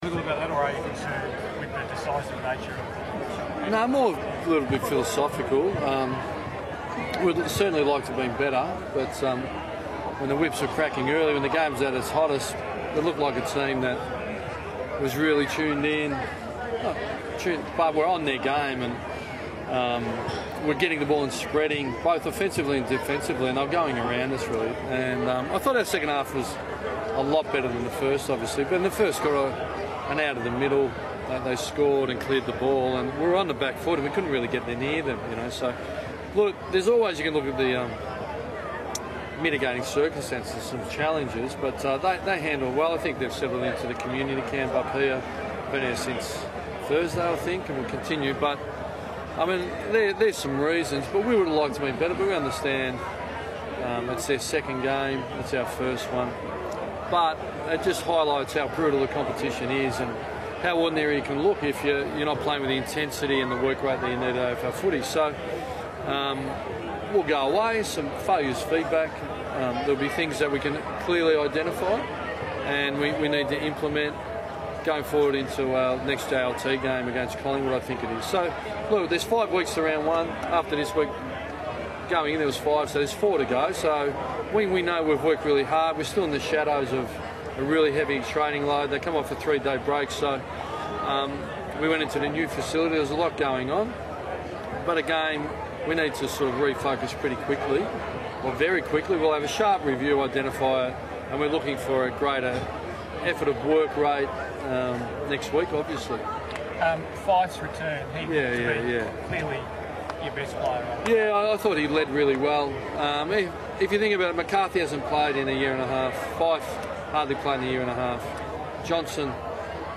Ross Lyon spoke to the media following the loss against West Coast